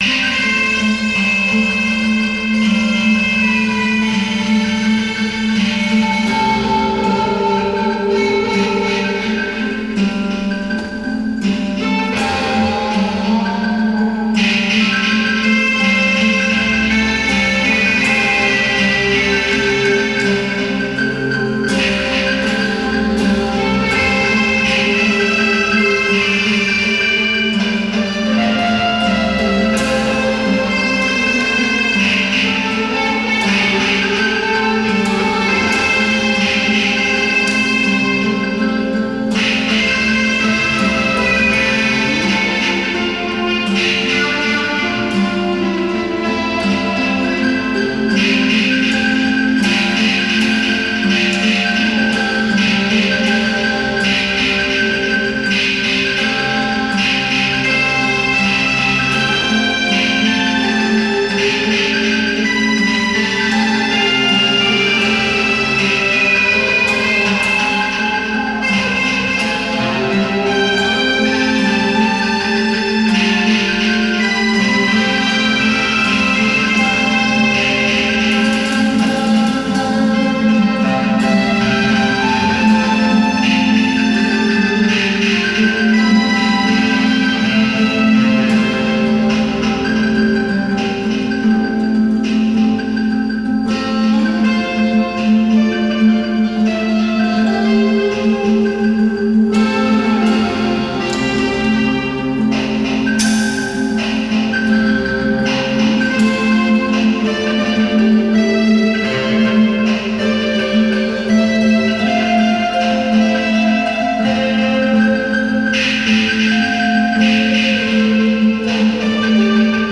a collective of five musicians